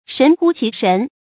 成語注音 ㄕㄣˊ ㄏㄨ ㄑㄧˊ ㄕㄣˊ
成語拼音 shén hū qí shén
神乎其神發音
成語正音 其，不能讀作“qī”。